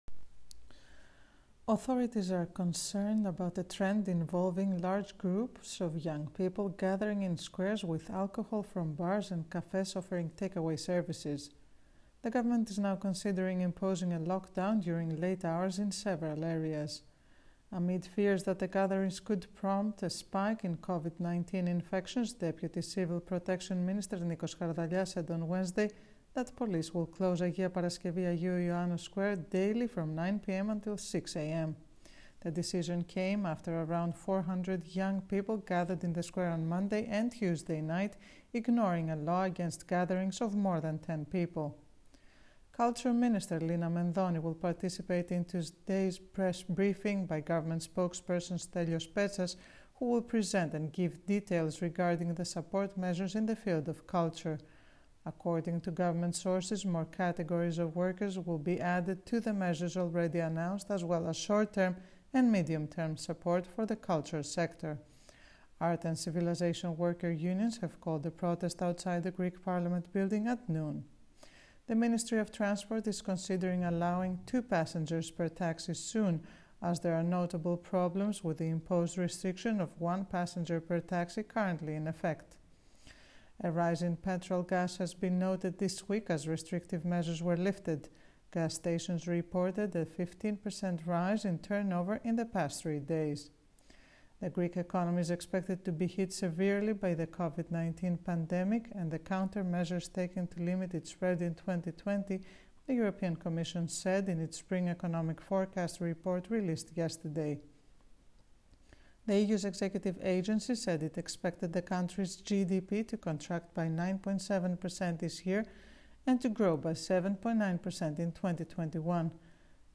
News in brief